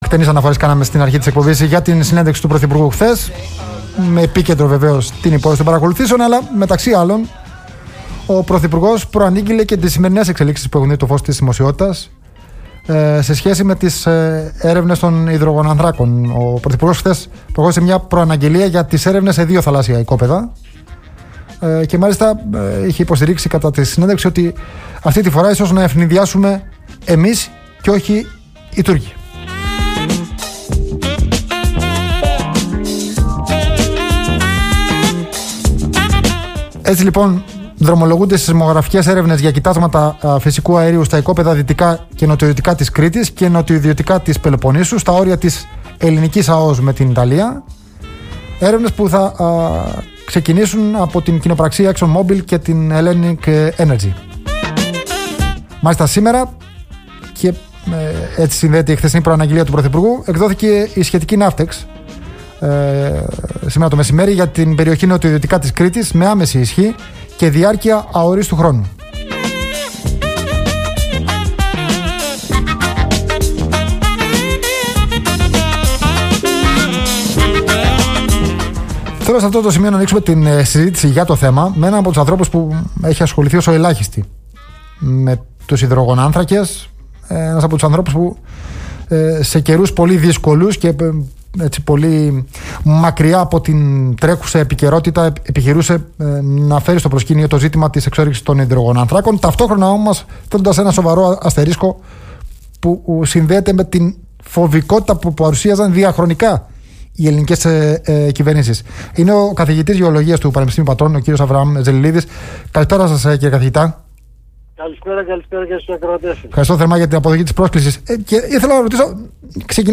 μιλώντας στον Politica 89.8